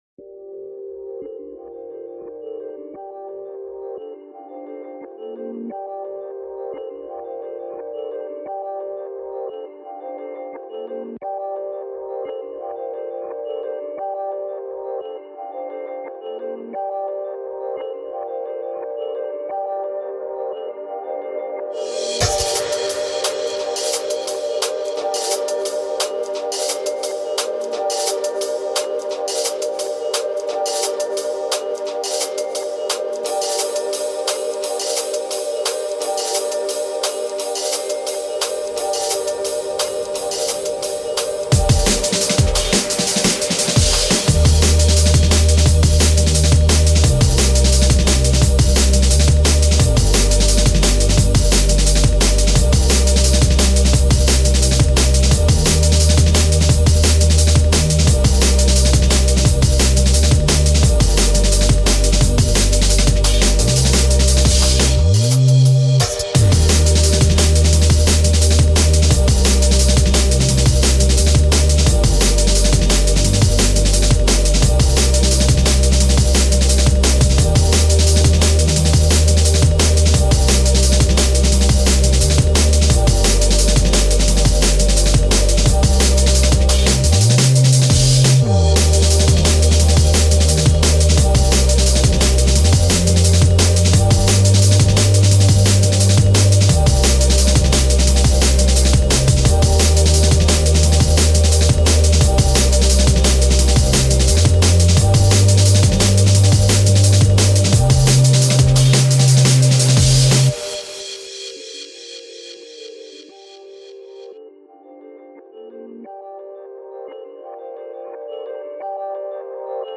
genre:dnb